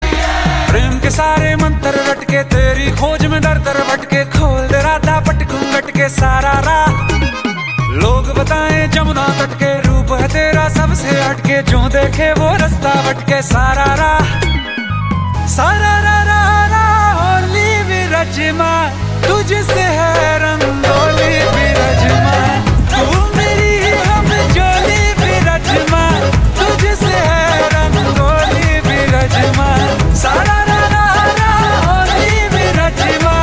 Categories Indian Festival Ringtones